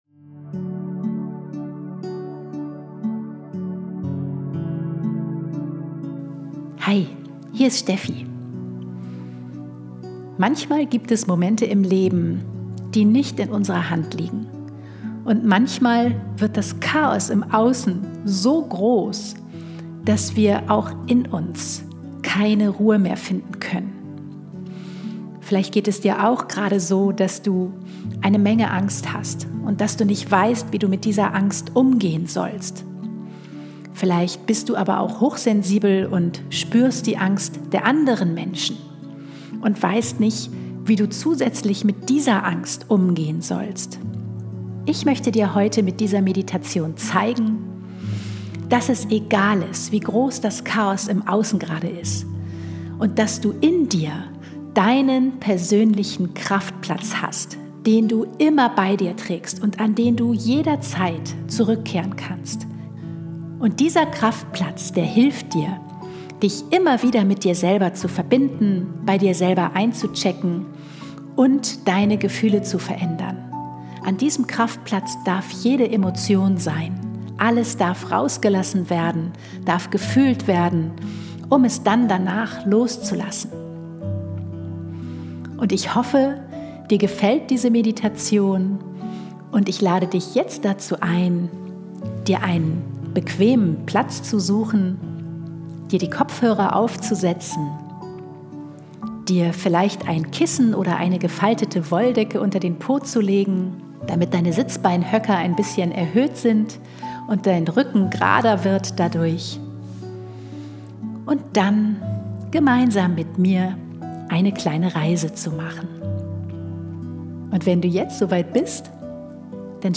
Geführte Meditation für mehr Leichtigkeit
folge22-meditation.mp3